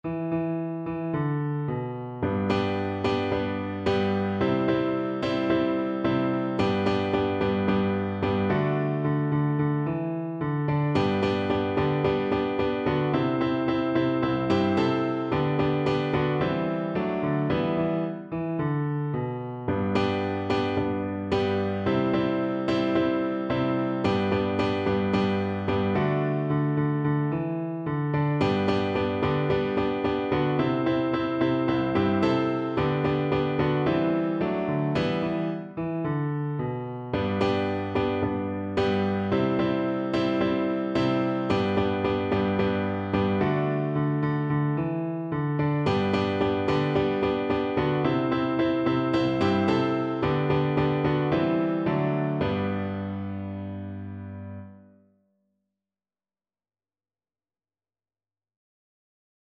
Piano version
Silang Mabele is a traditional South African folk song.
G major (Sounding Pitch) (View more G major Music for Piano )
4/4 (View more 4/4 Music)
With energy =c.110
Piano  (View more Easy Piano Music)
Traditional (View more Traditional Piano Music)